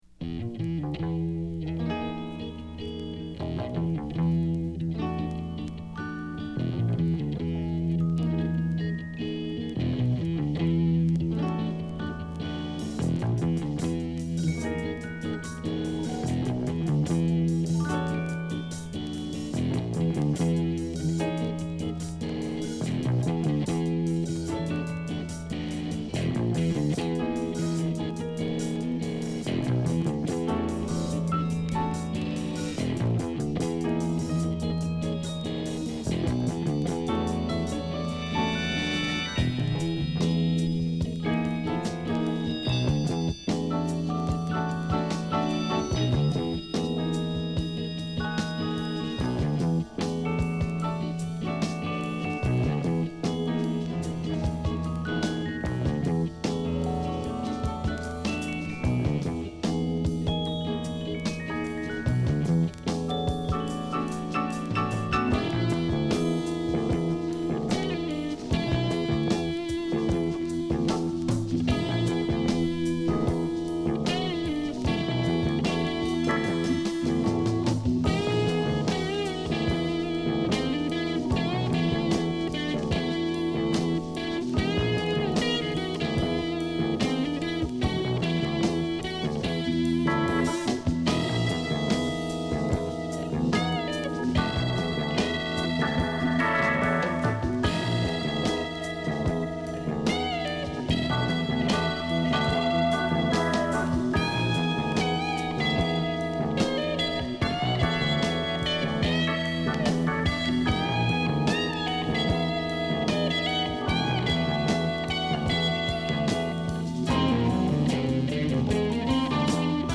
アルバム通して緊張感／勢いのあるジャズ・ロックを披露！